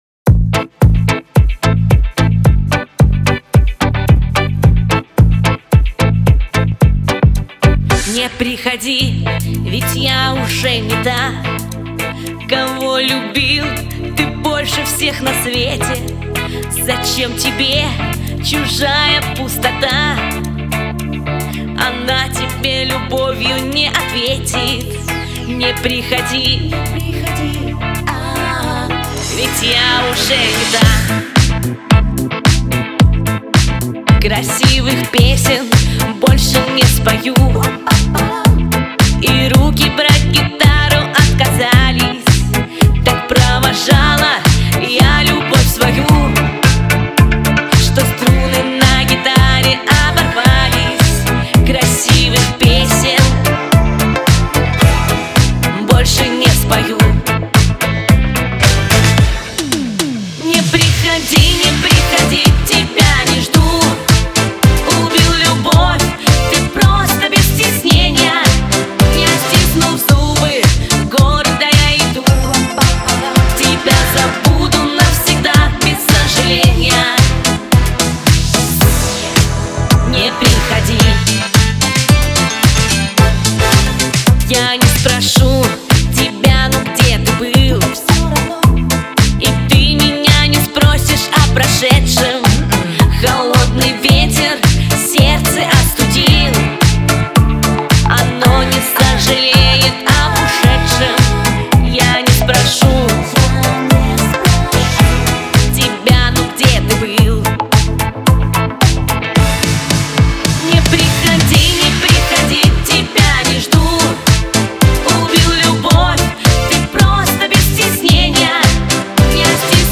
Лирика , диско